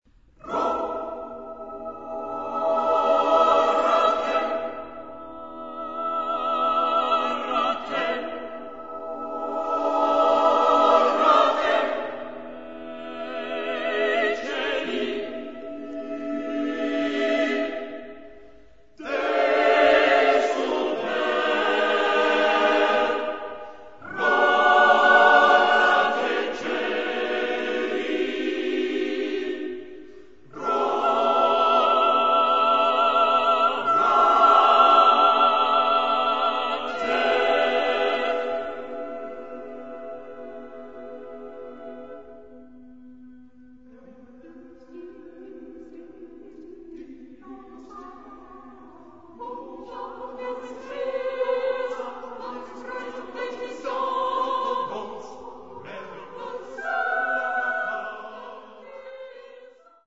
Alto
Bass
Choir
Soprano
Tenor